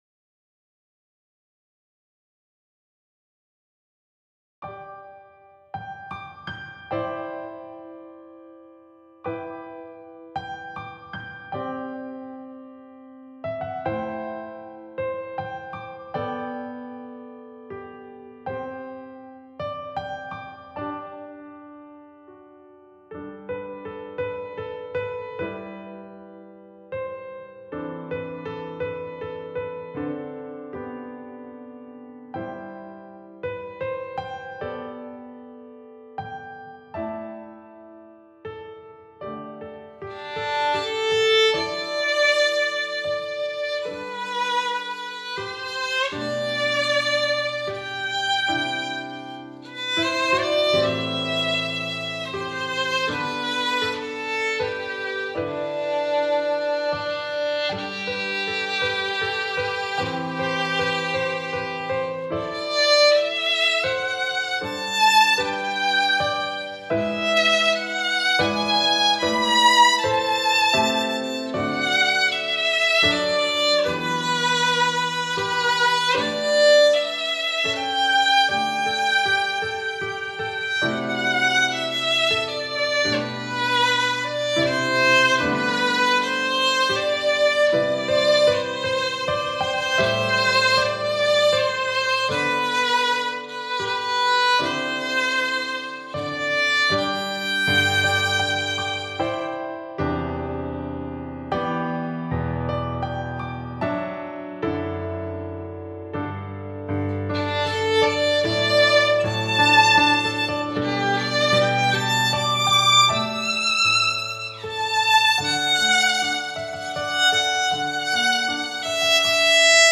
The second violin part also includes some pizzicato.
Download free recording of piano accompaniment and violin 2